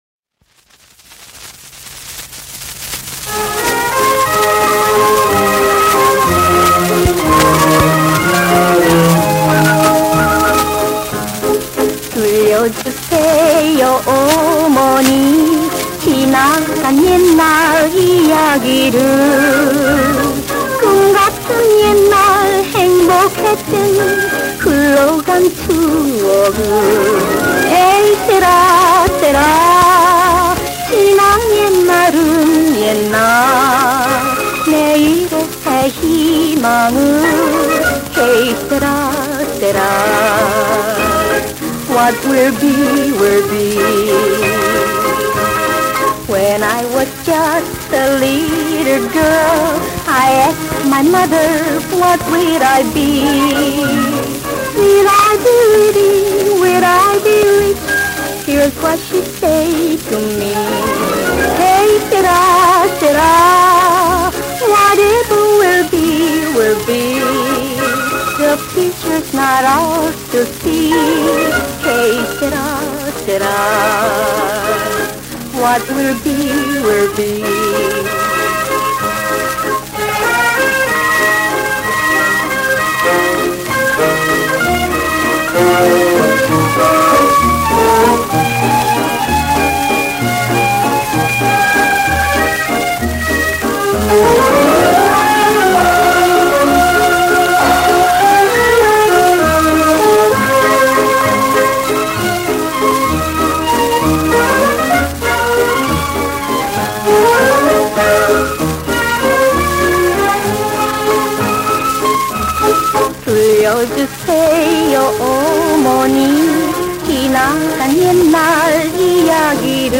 ♠그때 그 시절 옛 가요/★50~60년(측음기)
번안가요